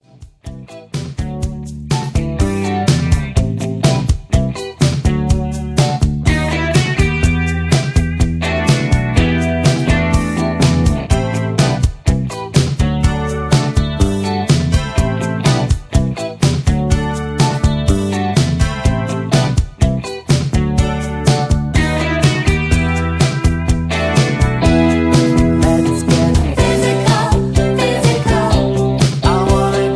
Karaoke MP3 Backing Tracks
Just Plain & Simply "GREAT MUSIC" (No Lyrics).
mp3 backing tracks